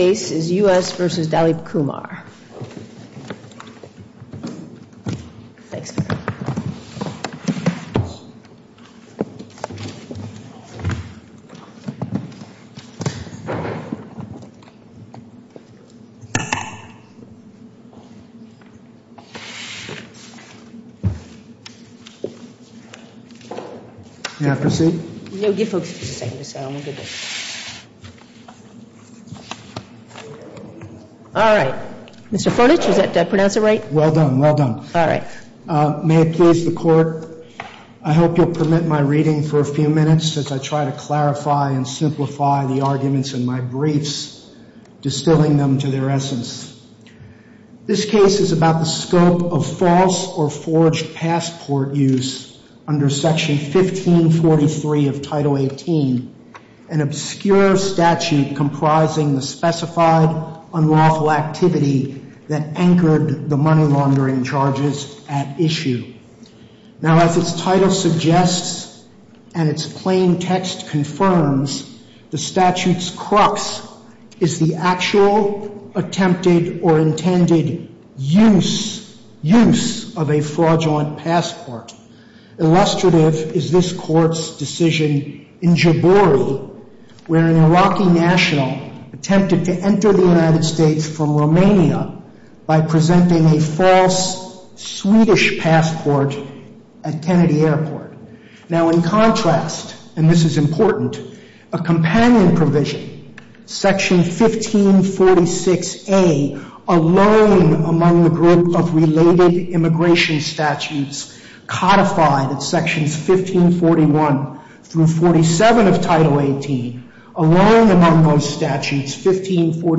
Oral Arguments for the Court of Appeals for the Second Circuit